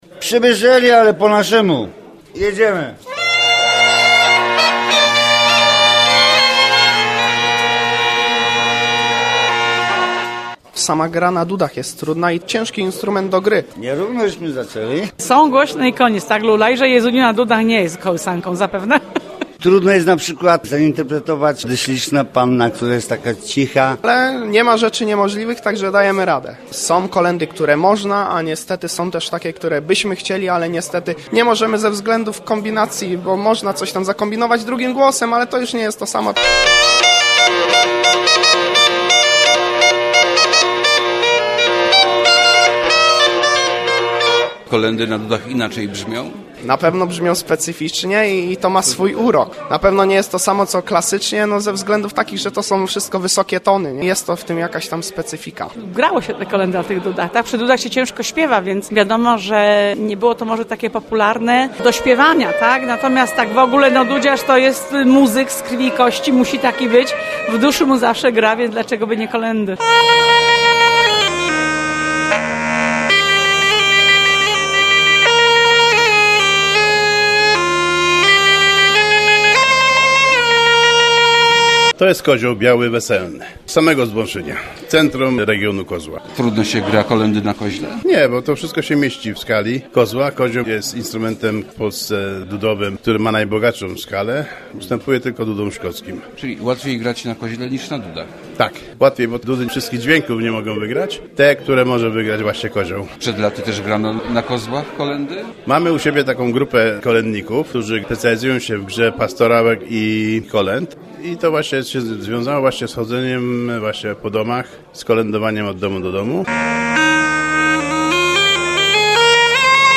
Kolędy na dudach. Po wielkopolsku
W Boszkowie niedaleko Leszna w ostatni weekend odbyły się warsztaty dudziarskie. Przyjechali na nie dudziarze z całego regionu, by wspólnie muzykować i szkolić młodych muzyków, którzy uczą się grać na tym wymagającym instrumencie.
zscj006xcge6q5n_koledy-na-dudach.mp3